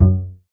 bass.ogg